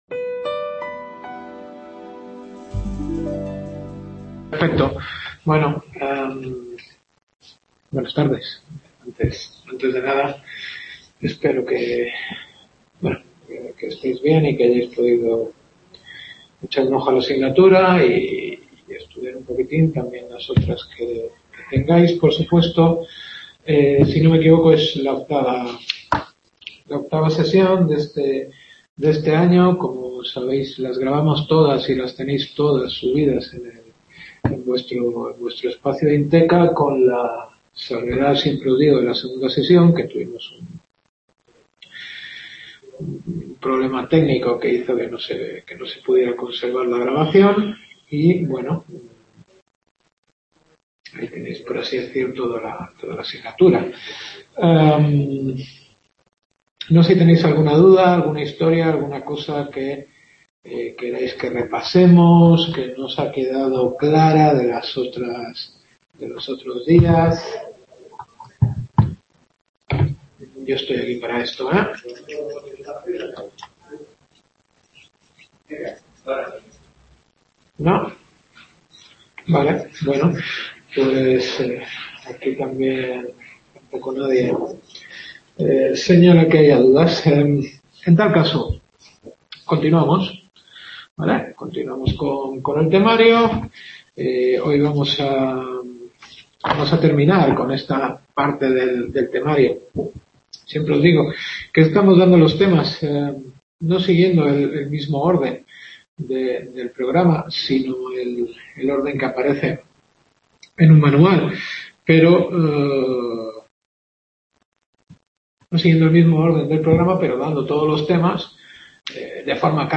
Octava clase.